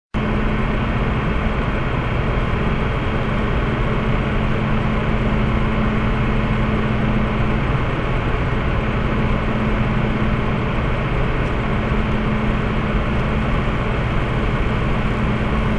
p.s 소음 녹음한 것
2. 팬 컨트롤러 최고 소음 (발열 제로)